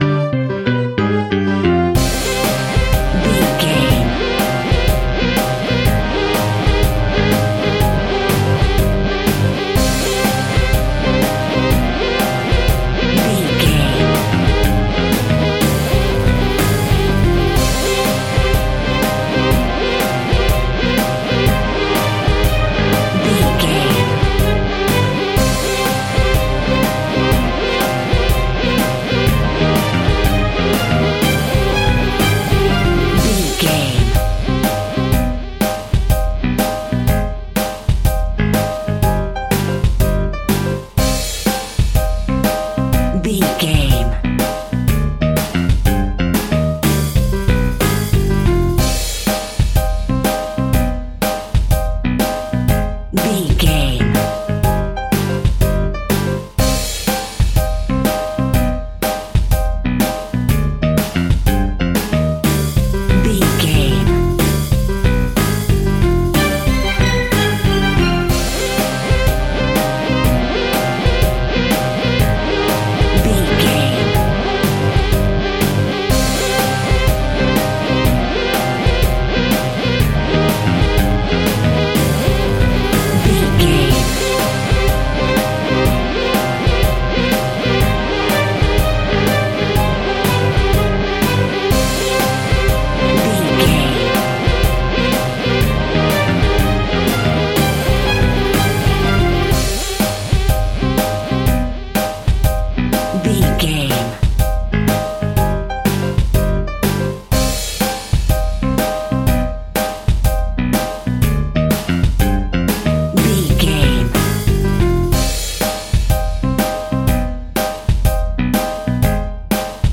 Aeolian/Minor
scary
ominous
dark
haunting
eerie
groovy
funky
strings
synthesiser
drums
piano
organ
spooky
pads